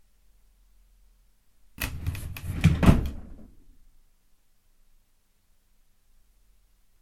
Fast/Open Drawer 3
Duration - 7 s Environment - Bedroom, absorption of curtains, carpet and bed. Description - Opens, pulled fast, grabs, rolls, wheels, wooden drawer, bangs when opens fully.